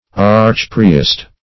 Archpriest \Arch`priest"\, n.